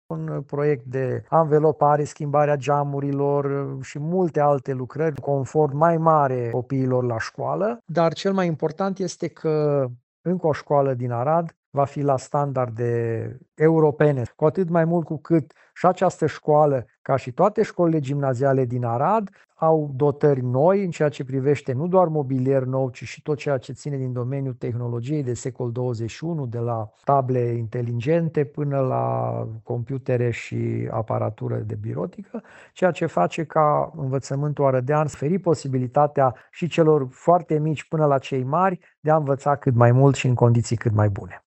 Primarul Aradului, Călin Bibarț, spune că, la fel ca în alte cazuri, lucrările se vor desfășura în timp ce elevii învață în școală.